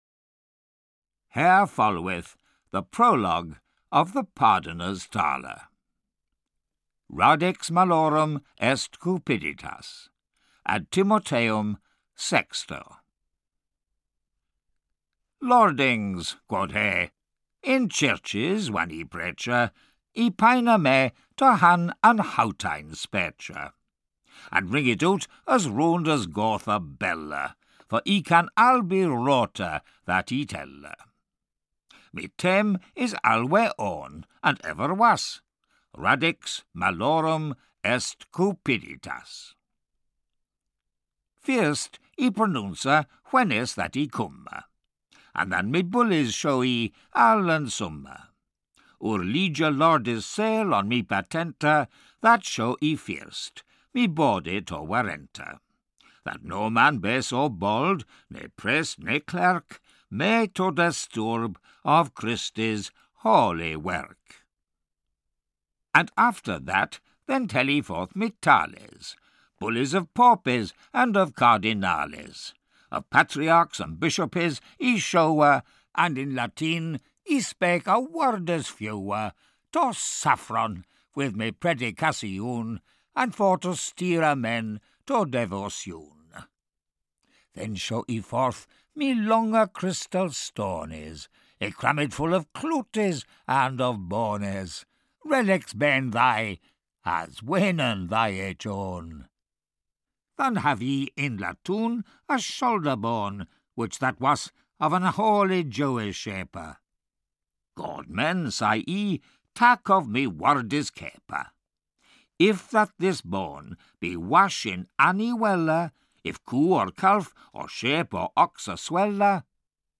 Audiobook The Pardoners Tale written by Geoffrey Chaucer.